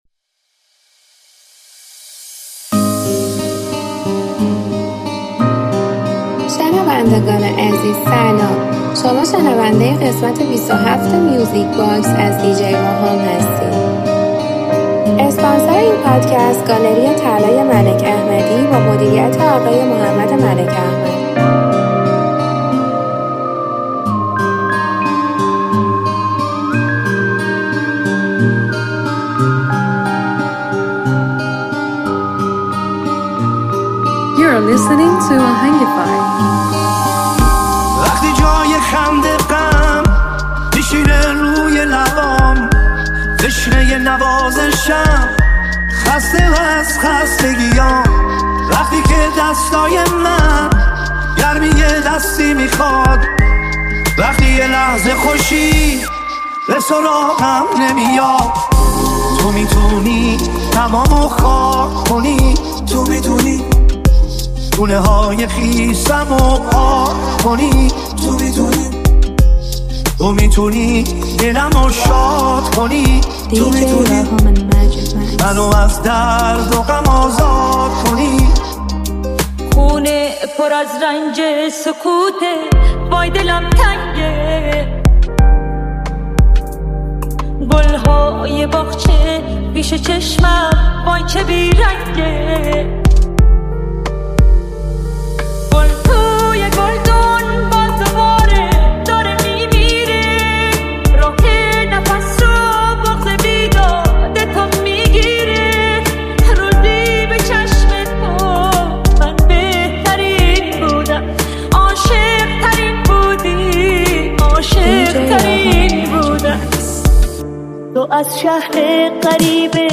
ریمیکس جدید و پرانرژی